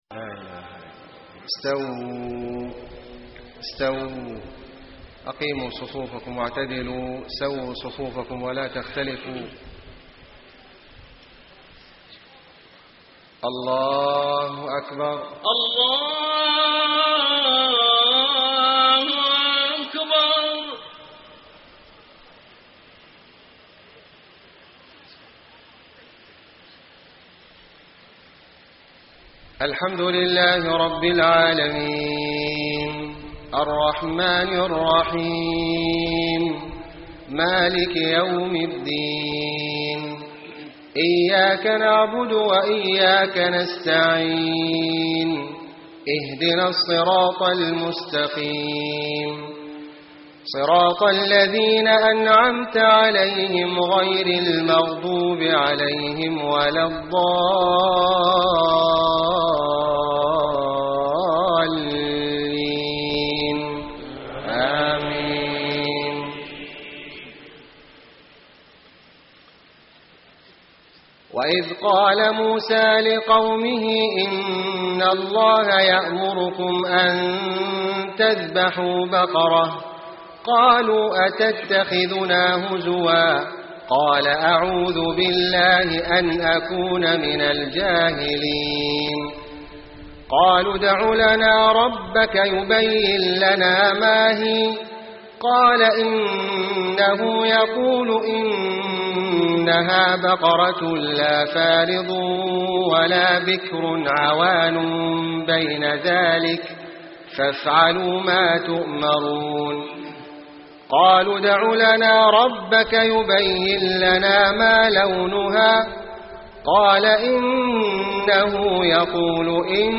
صلاة العشاء 2-6-1434 من سورة البقرة > 1434 🕋 > الفروض - تلاوات الحرمين